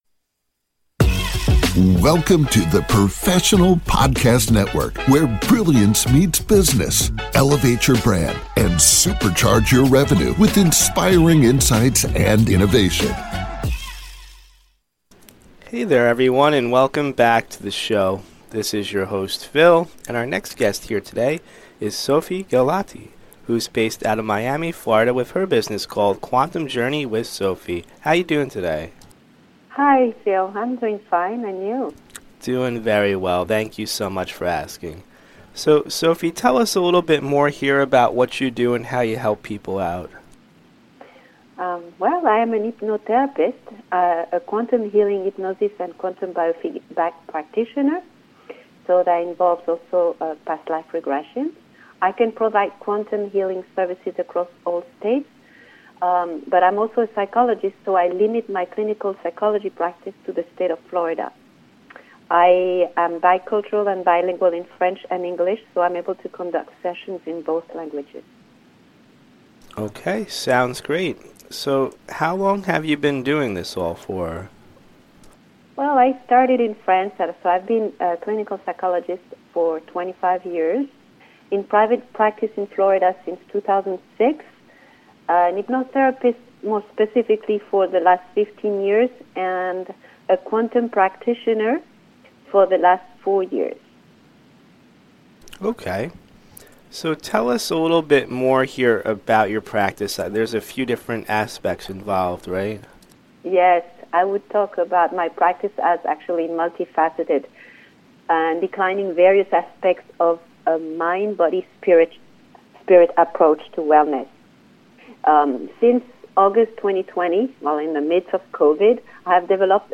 🎧 Podcast Interview — January 2025 (mp3)